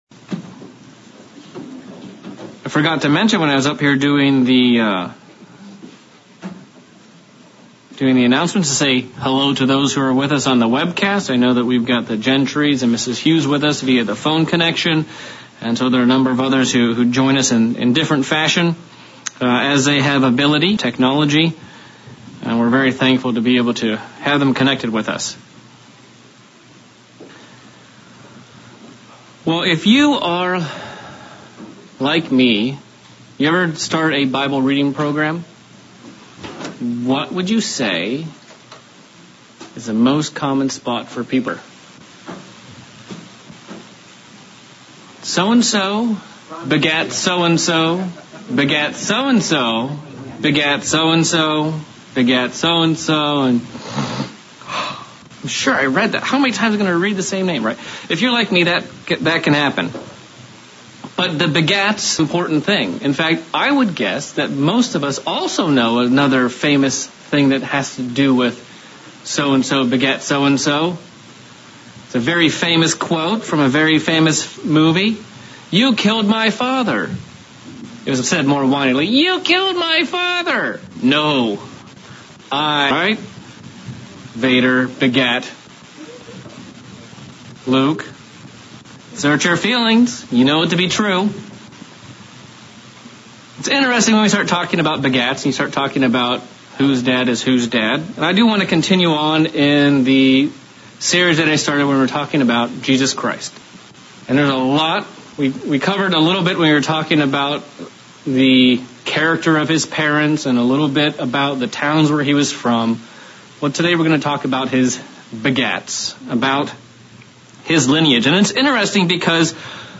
Sermon looking at the story of Jesus Christ and the 4 titles he used. A - Son of David B - Son of Abraham C - Son of God D - Son of Man This sermon shows how he fulfilled each of these in scripture and how he used them differently in his daily interactions with various people and groups